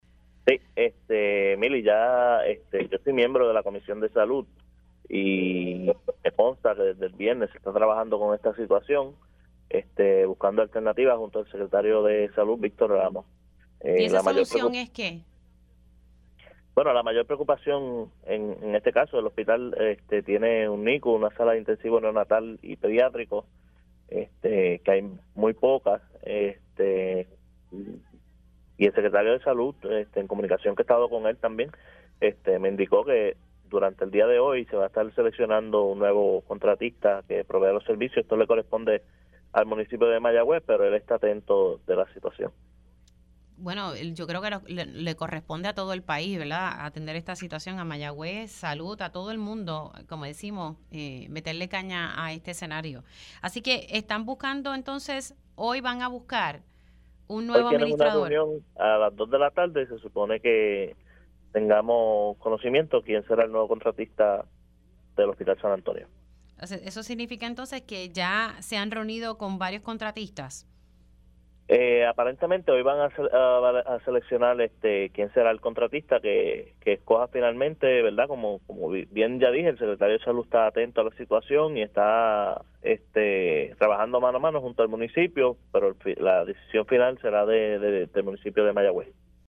El senador por el Distrito de Mayagüez-Aguadilla, Jeison Rosa, confirmó en Pega’os en la Mañana que en la tarde de hoy, jueves, el municipio de Mayagüez escogerá a un nuevo administrador para el hospital San Antonio, cuyas facilidades y administración fueron transferidas el pasado viernes para evitar su cierre.
409-JAISON-ROSA-SENADOR-MAYAGUEZ-HOY-MUNICIPIO-DE-MAYAGUEZ-ESOCGE-NUEVO-ADMINISTRADOR-PARA-HOSPITAL-SAN-ANTONIO.mp3